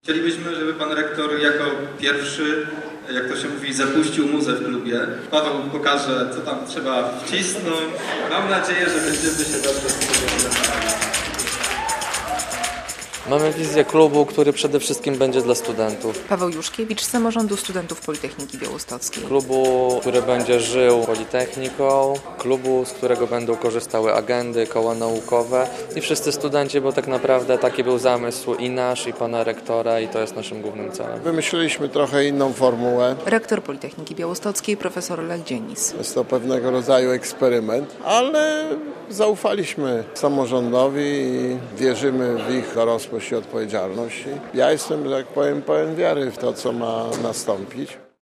Studenci rozkręcają Gwint - relacja